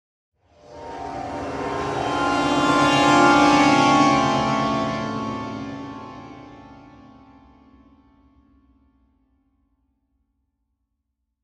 Нагоняющие страх звуки для монтажа видео и просто испугаться слушать онлайн и скачать бесплатно.